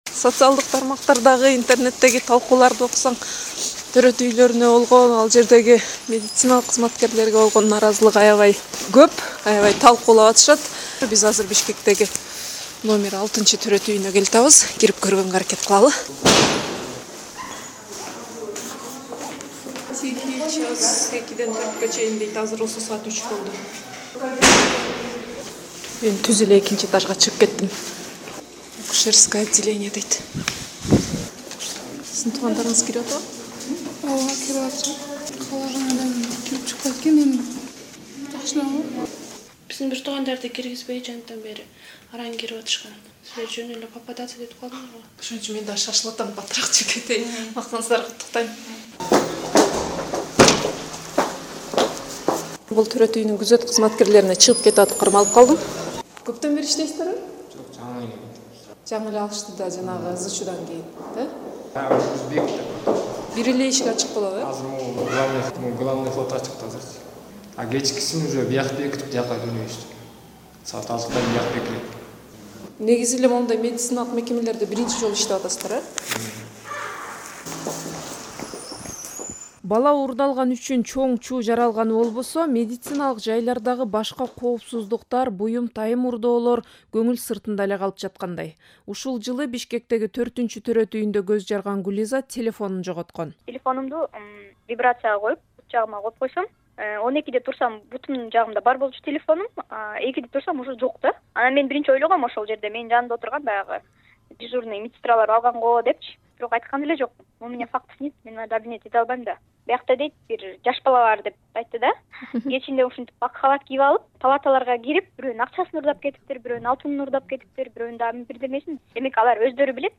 KYRGYZ/HEALTH/ Special Radio Package: The recent theft of a newborn from the maternity hospital in Bishkek raised question on security in maternity hospitals